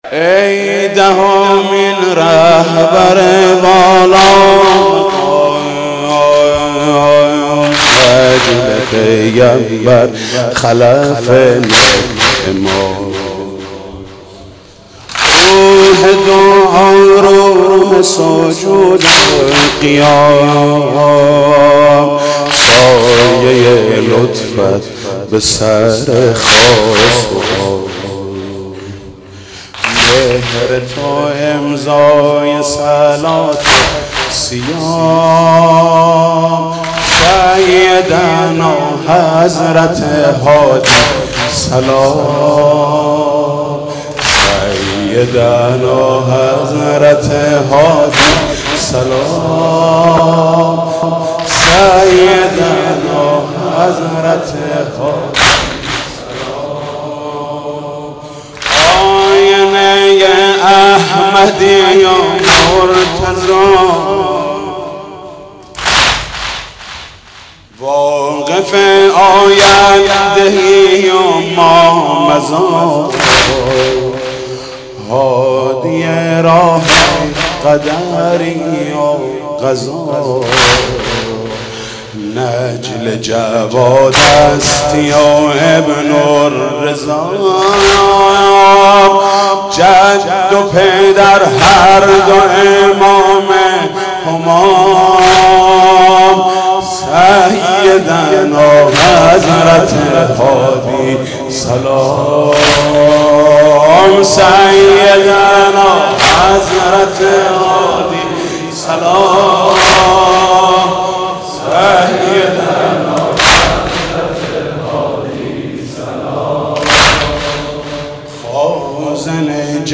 مداحی حاج محمود کریمی بمناسبت شهادت امام هادی (ع)